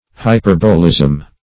Search Result for " hyperbolism" : The Collaborative International Dictionary of English v.0.48: Hyperbolism \Hy*per"bo*lism\, n. [Cf. F. hyperbolisme.]
hyperbolism.mp3